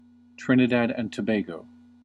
1. ^ Pronounced /ˈtrɪnɪdæd ...təˈbɡ/
, /- t-/, TRIN-ih-dad ... tə-BAY-goh, - toh-
En-us-Trinidad_and_Tobago.ogg.mp3